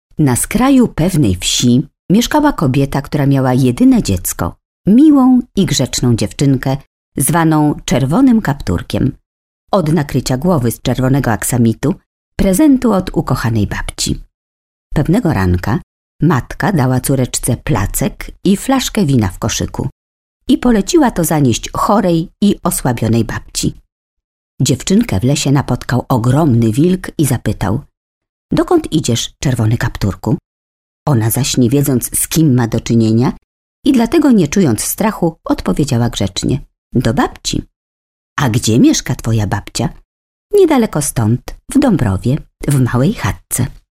Female 50 lat +
Mature voice, ideal for reportage, journalism and fairy tales.